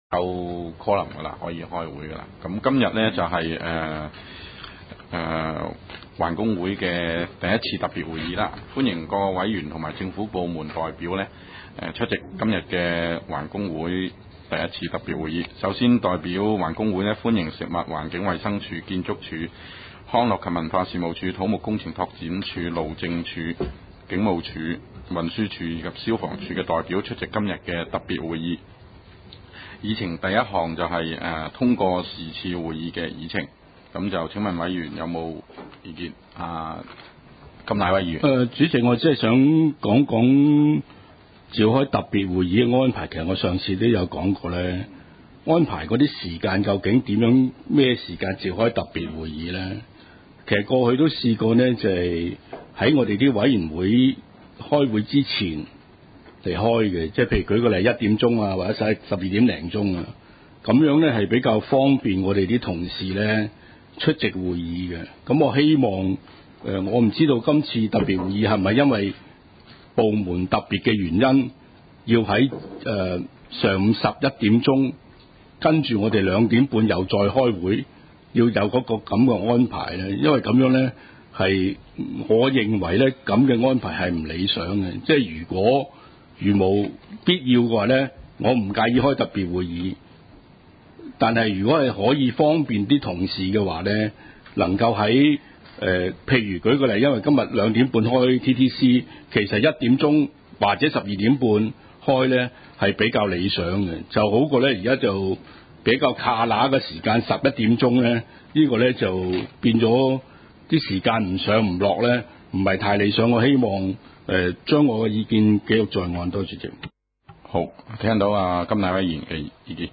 委员会会议的录音记录
中西区区议会会议室